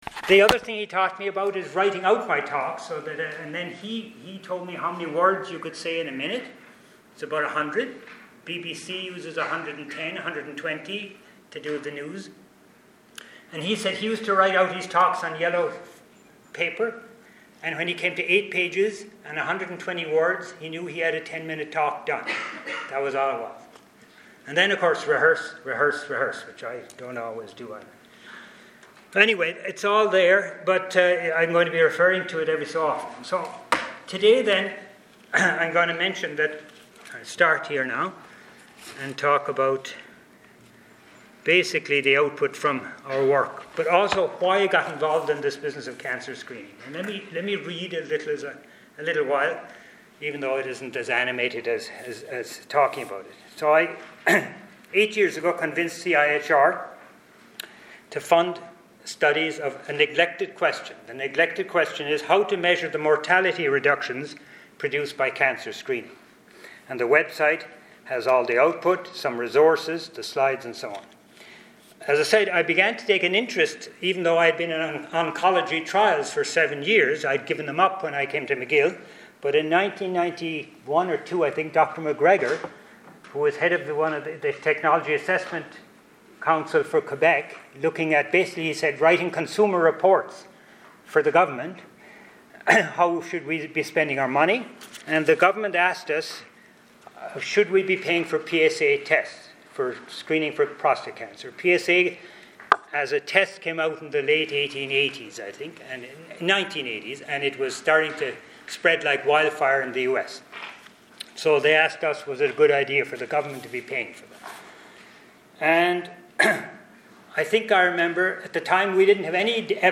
Measuring the mortality reductions produced by organized cancer screening: a principled approach Biostatistics Branch Seminar Series